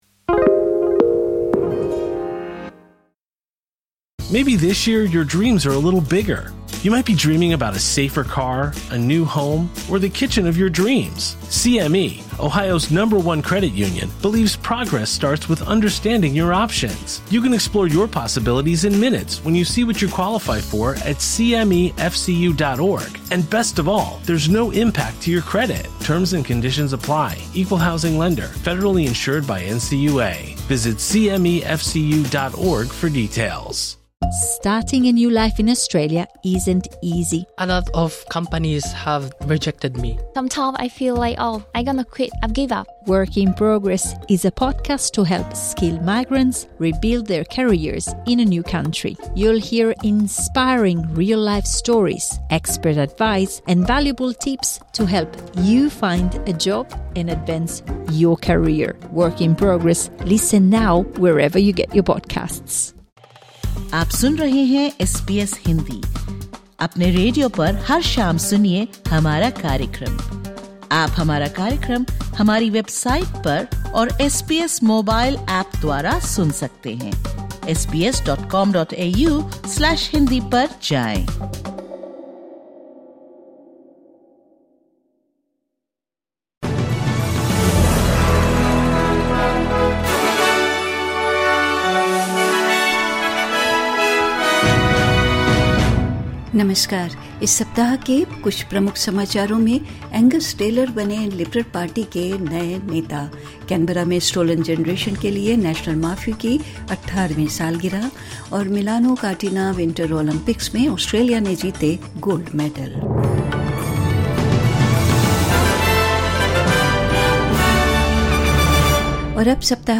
SBS Hindi News Wrap 14 February 2026